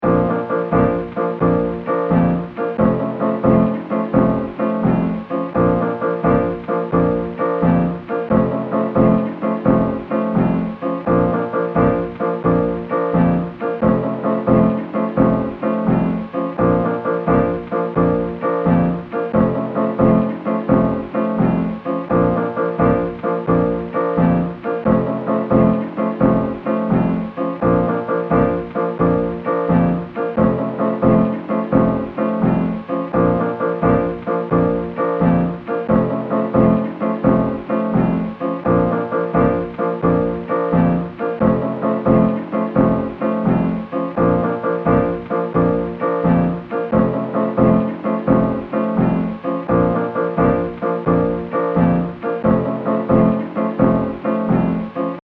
The following audio files have been created using tracks from Open Music Archive.
Loop Two